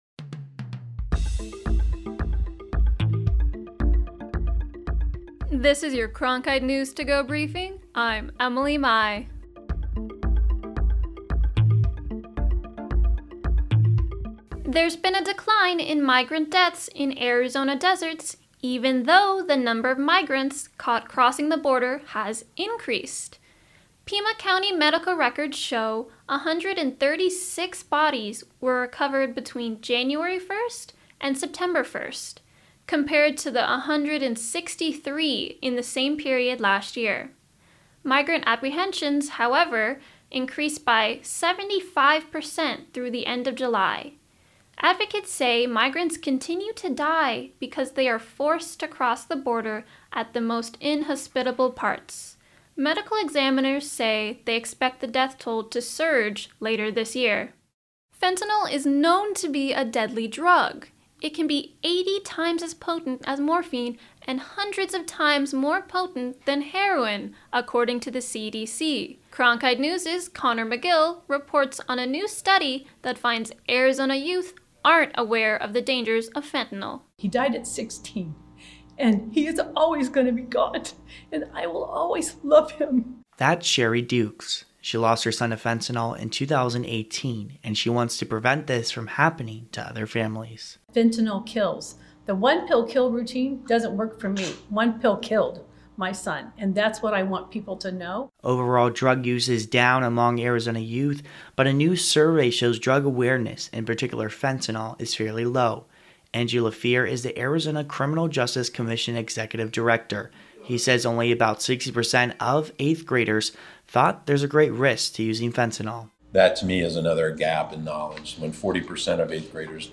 (Bring up music briefly and duck below and out )